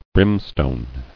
[brim·stone]